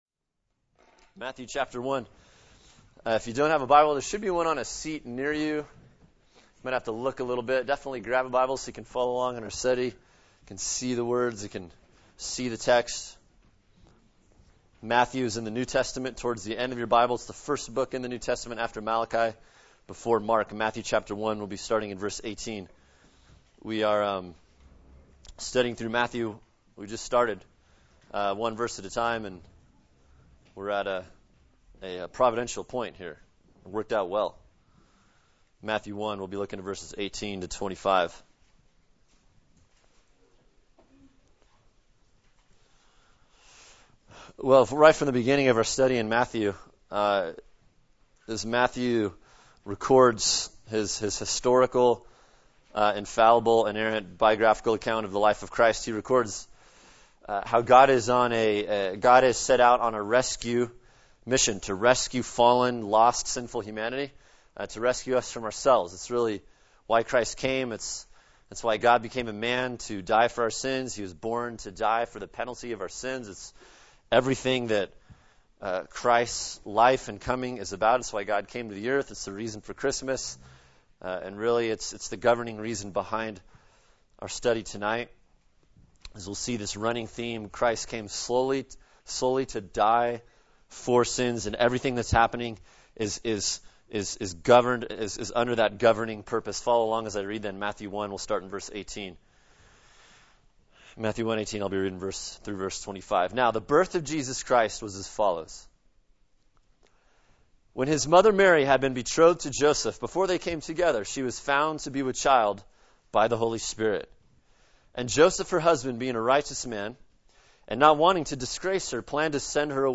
[sermon] Matthew 1:18-25 “How Our Rescue Began” | Cornerstone Church - Jackson Hole